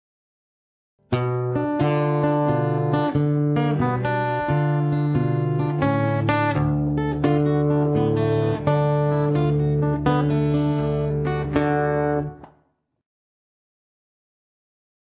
Not only was he one of the greatest blues guitarists of all time, but Blake also seems to have been the primary developer of “finger-style” ragtime on the guitar, the six-string equivalent to playing ragtime on the piano.
It’s tuned to drop D. Pay close attention to the super-fast triplets occurring throughout much of the song (notated with a “3” in the traditional music score).